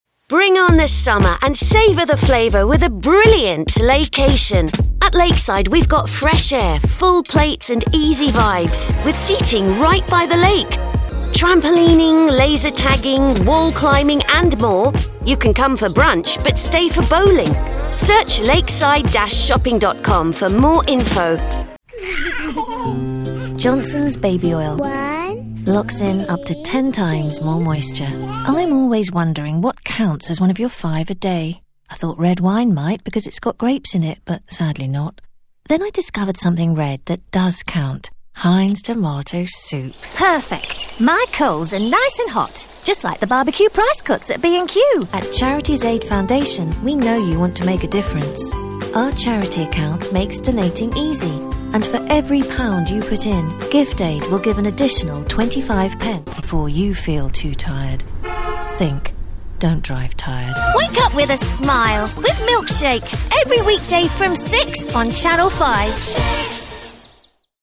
Posh voice over artists represented by London based voice-over agency Voice Squad